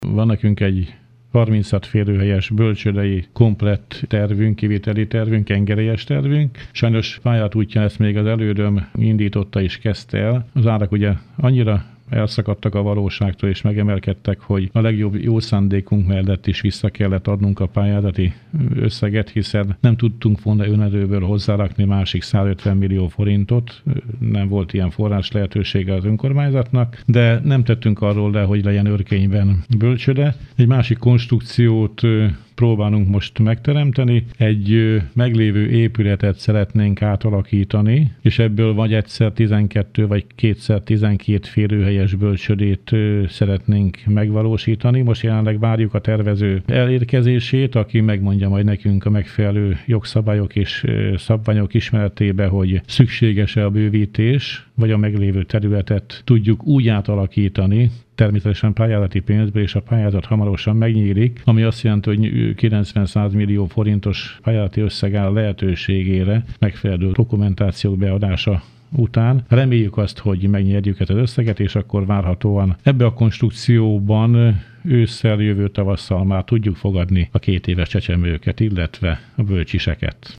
Hírek
Dr. Tüske Zoltán polgármester beszélt az új kezdeményezésről.